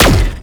Zapper_1p_02.wav